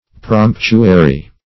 Promptuary \Promp"tu*a*ry\, a.